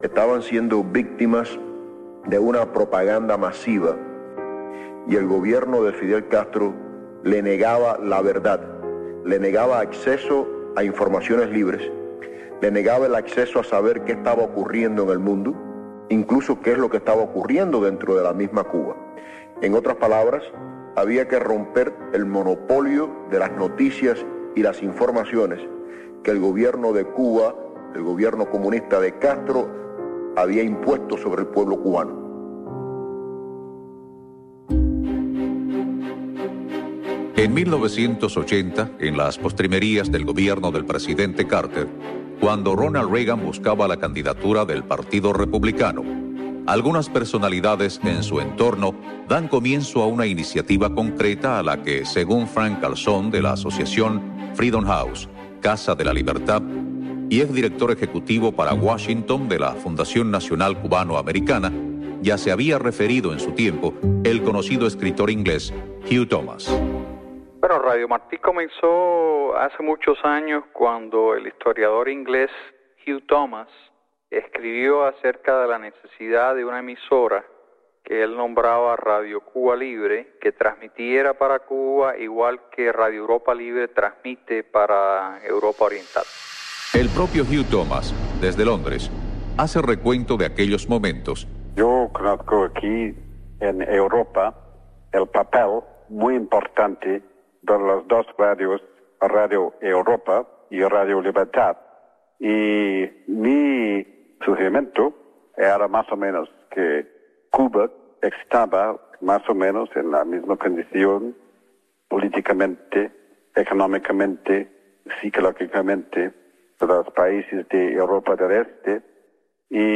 El gobierno cubano desató una oleada represiva tras las protestas del 11 de julio en Cuba. Madres de las víctimas y madres arrestadas en el contexto de las manifestaciones, dan testimonios de violaciones a los derechos humanos y judiciales en Cuba.